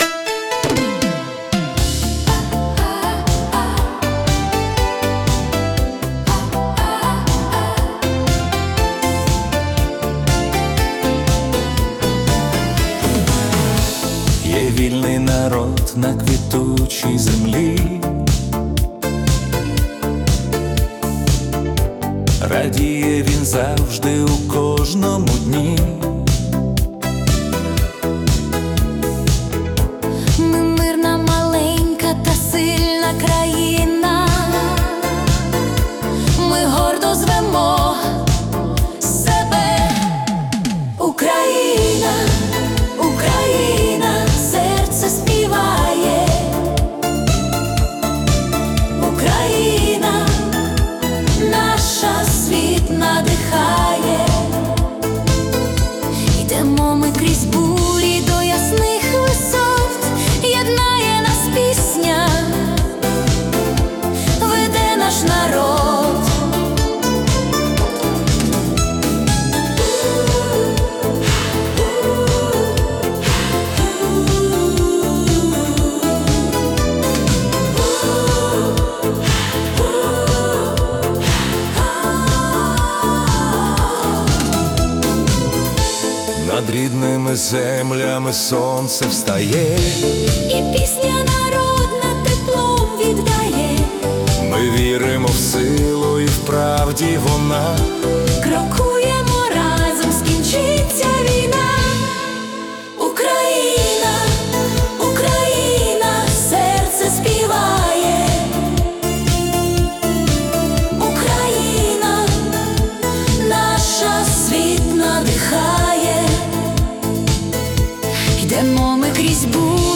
🎵 Жанр: Italo Disco / Patriotic